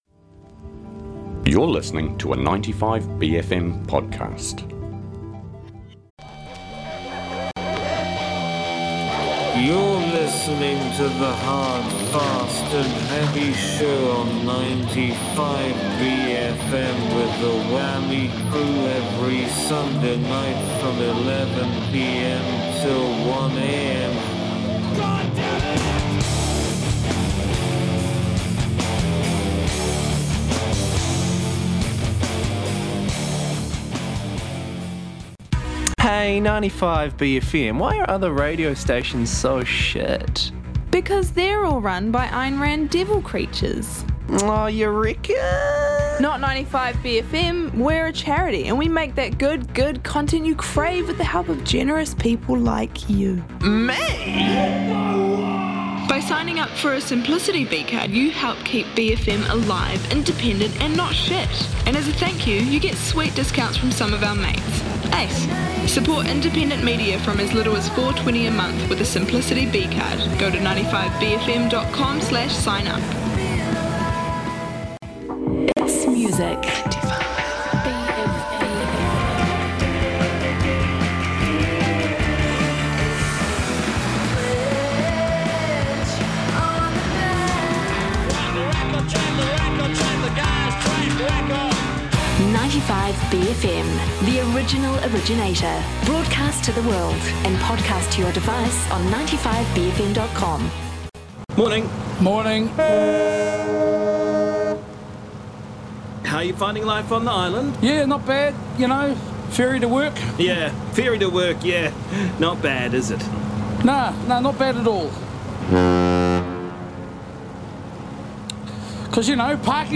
Two hours of punk, metal, hip hop, hyperpop and everything Hard, Fast and Heavy.